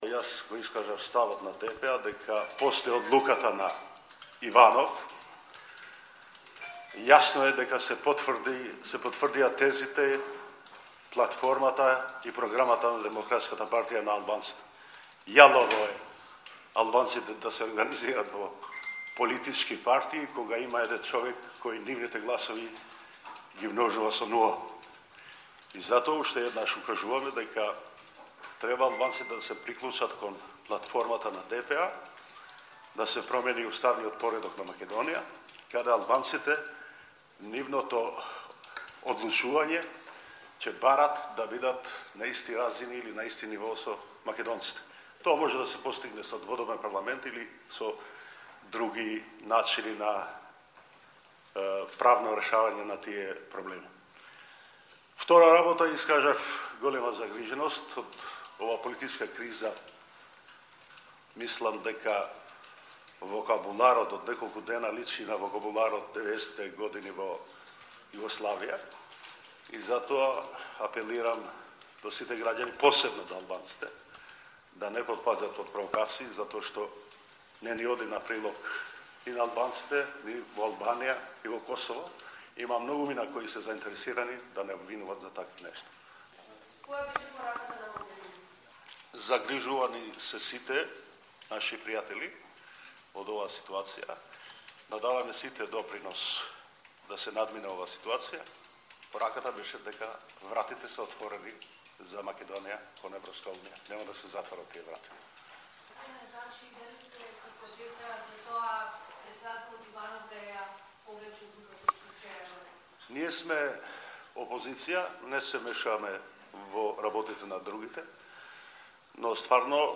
Изјава на Мендух Тачи по средбата со Федерика Могерини во Скопје